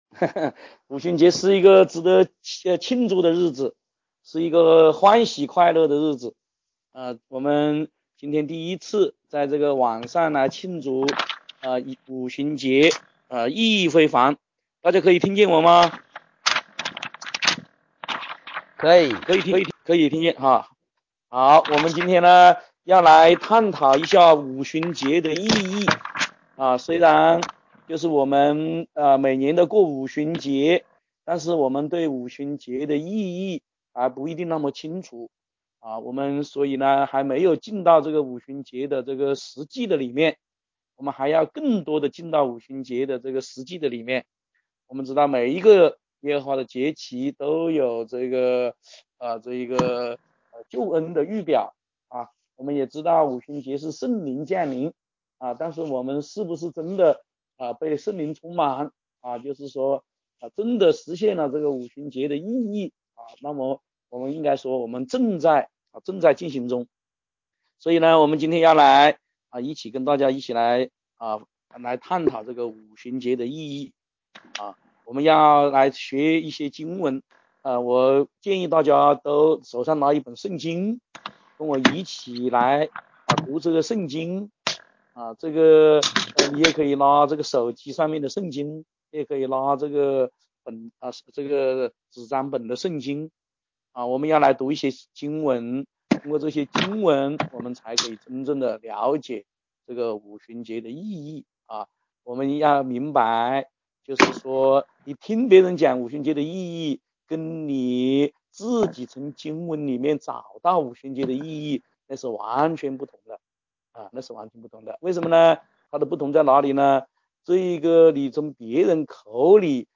5777五旬节聚集